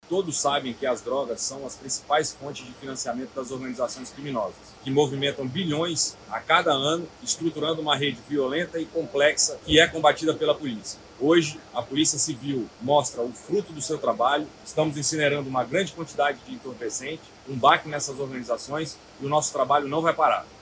Estima-se que o material represente um prejuízo de aproximadamente R$ 195 milhões ao crime organizado, como explica o delegado-geral da PC-AM, Bruno Fraga.